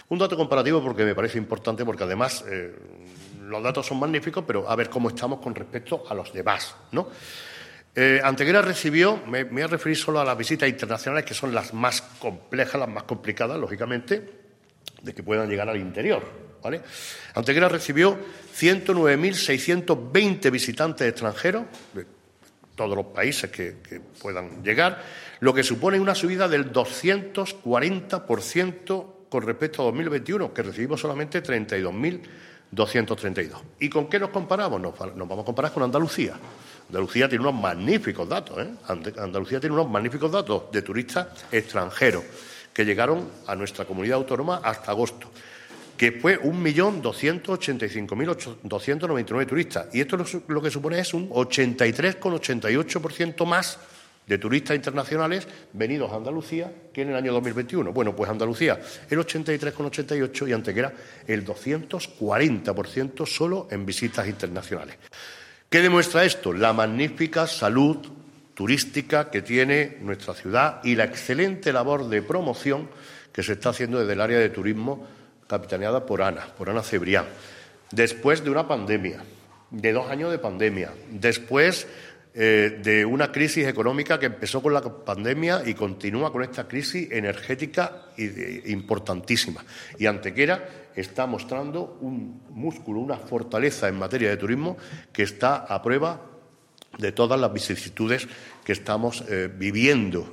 El alcalde de Antequera, Manolo Barón, y la teniente de alcalde delegada de Turismo, Ana Cebrián, han informado hoy en rueda de prensa sobre los datos más relevantes que ha dado de sí el turismo en nuestra ciudad entre los meses de enero y septiembre del presente año 2022.
Cortes de voz